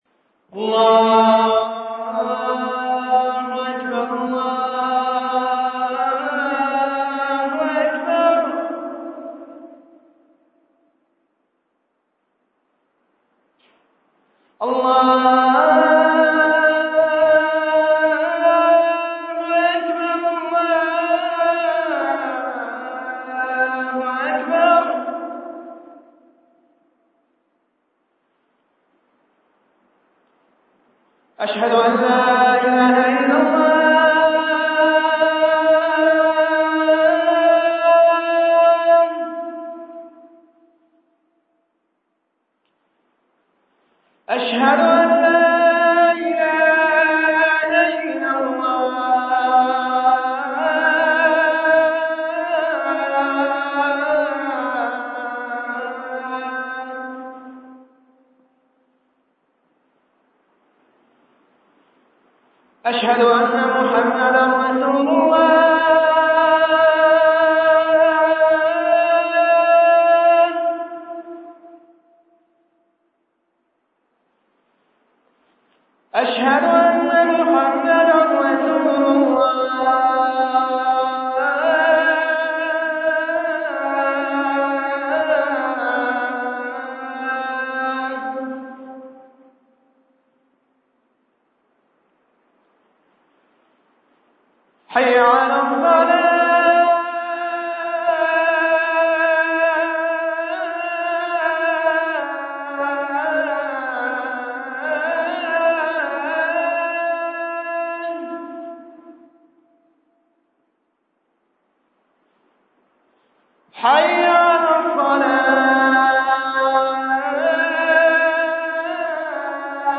أناشيد ونغمات
أذان